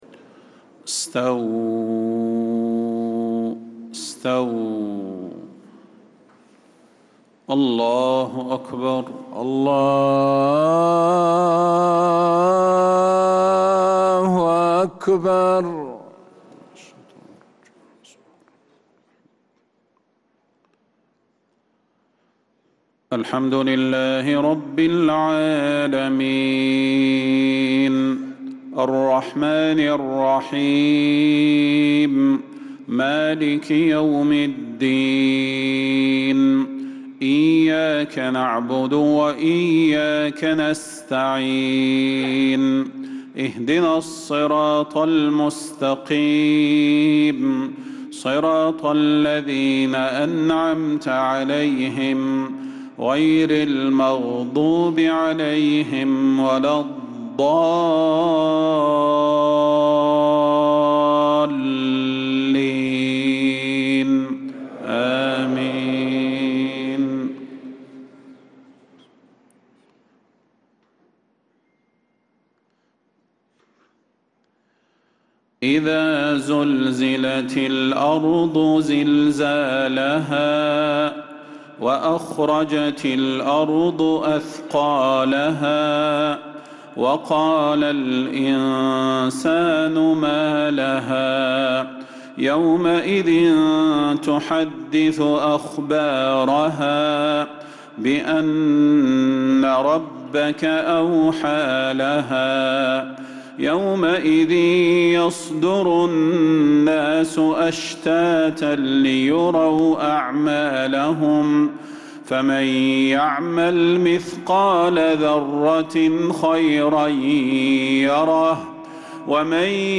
مغرب الجمعة 4 ذو القعدة 1446هـ سورتي الزلزلة و القارعة كاملة | Maghrib prayer Surat Az-Zalzala and al-Qari`ah 2-5-2025 > 1446 🕌 > الفروض - تلاوات الحرمين